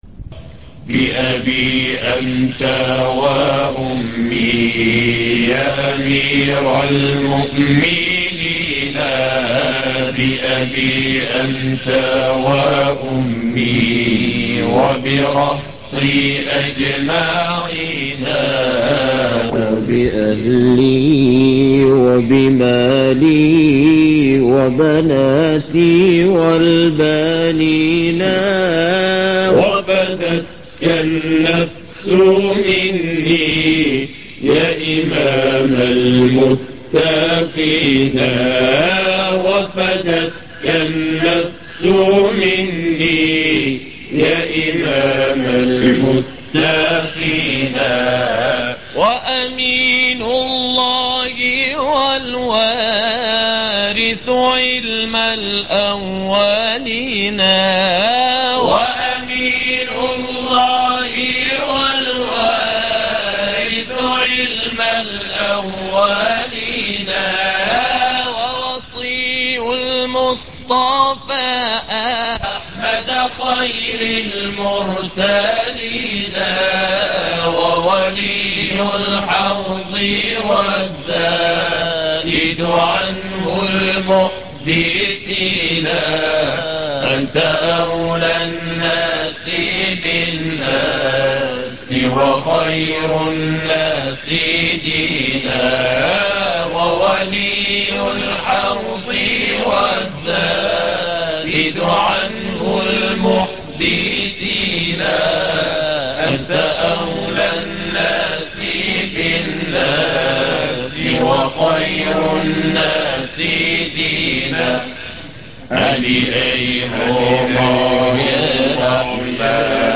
بابي انت وامي ياأمير المؤمنين ـ مقام الحجاز - لحفظ الملف في مجلد خاص اضغط بالزر الأيمن هنا ثم اختر (حفظ الهدف باسم - Save Target As) واختر المكان المناسب